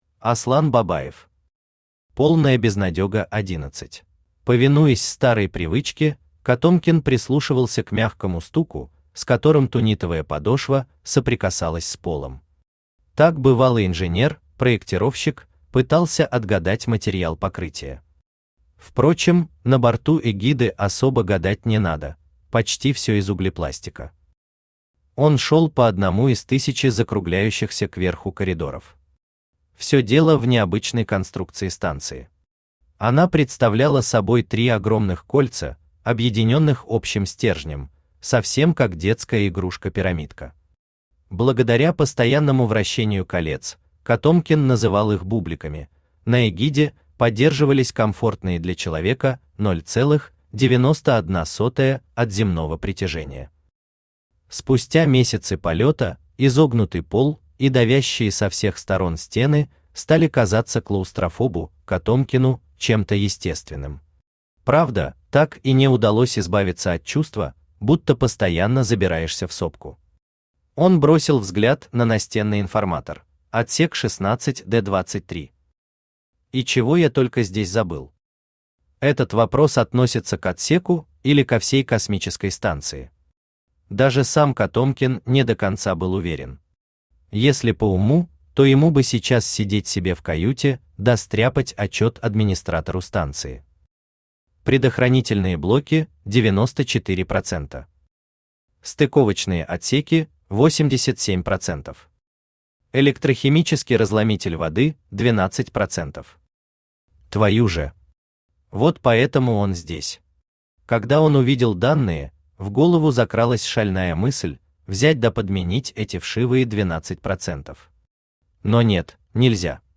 Aудиокнига Полная Безнадёга 11 Автор Аслан Айдынович Бабаев Читает аудиокнигу Авточтец ЛитРес.